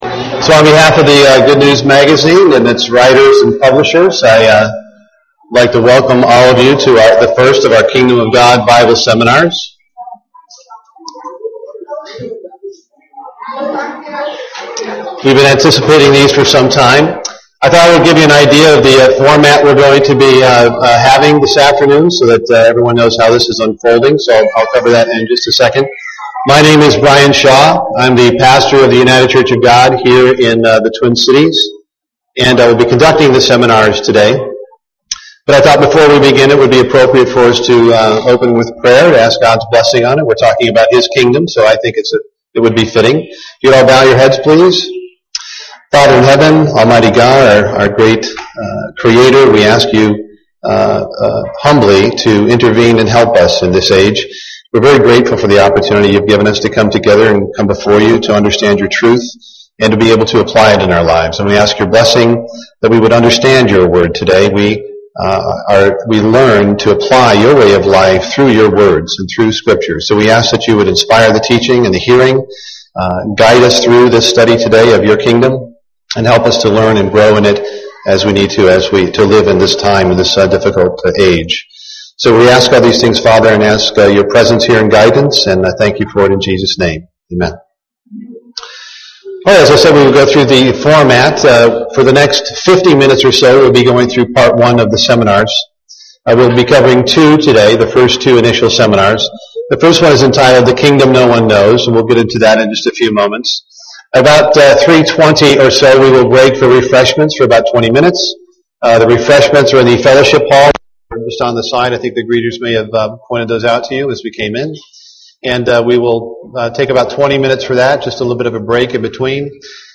What is the gospel of the Kingdom of God? Learn more about this missing dimension in human understanding and the teachings of modern Christian teaching in this Kingdom of God seminar.
UCG Sermon Studying the bible?